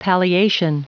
Prononciation du mot palliation en anglais (fichier audio)
Prononciation du mot : palliation